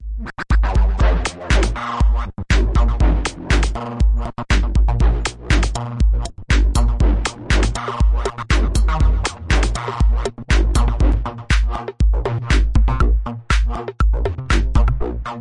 描述：包含类似声音的共振峰的节拍。
Tag: 声乐样的 复杂的共振峰 过滤 打败